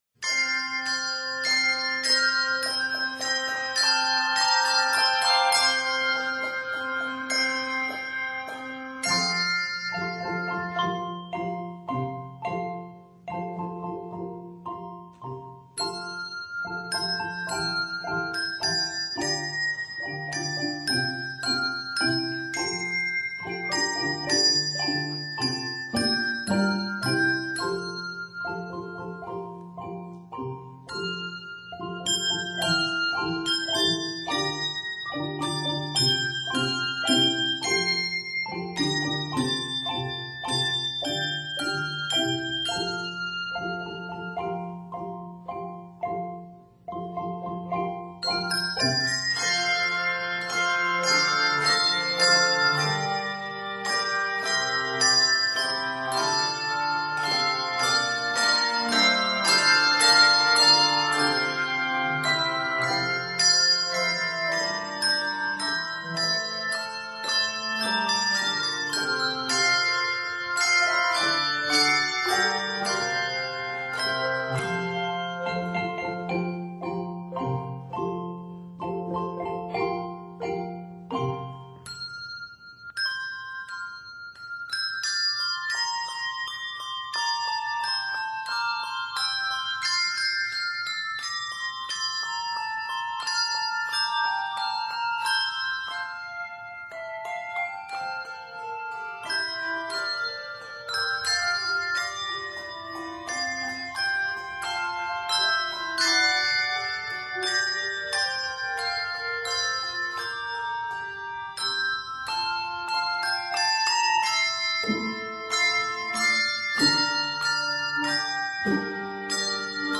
is scored in F Major and G Major